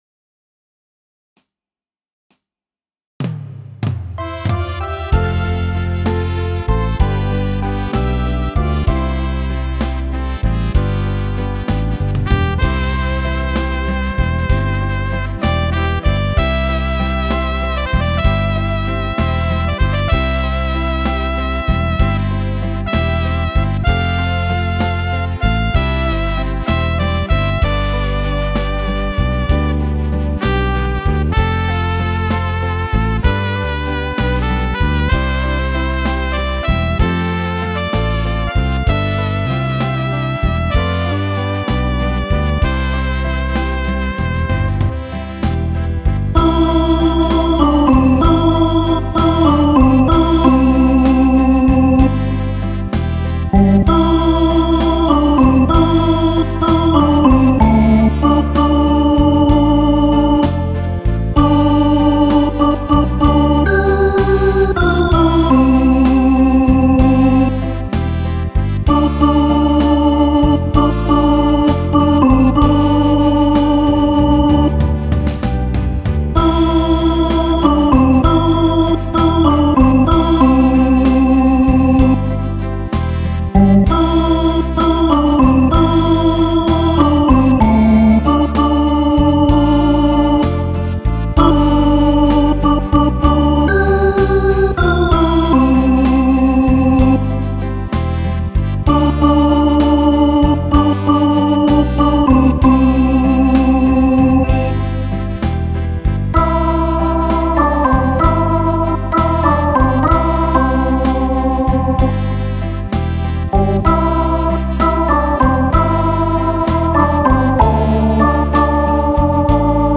un bon style et avec de beaux sons d'orgue,
Excellent slow rock ave orgue et trompette, j'adore
les voices sont très jolies, Bravocoolcool